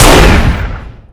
RifleSht.ogg